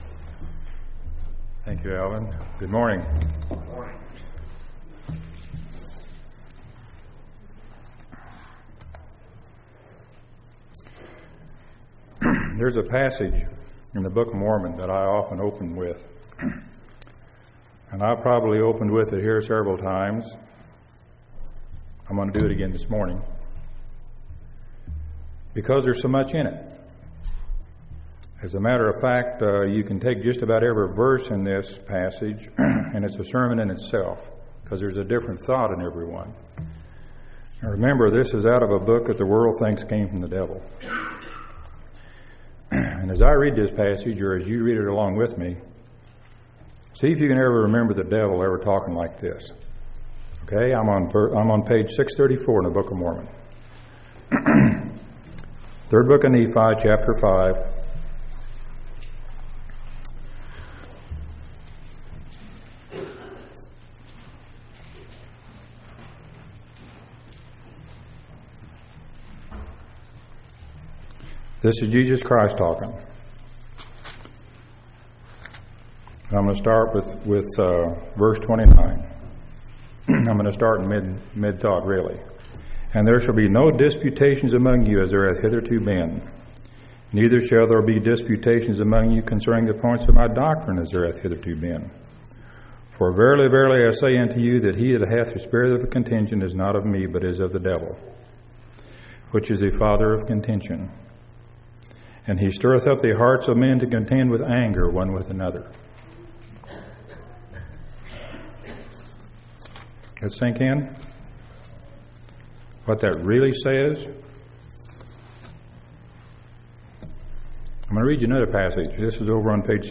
2/11/2001 Location: Temple Lot Local Event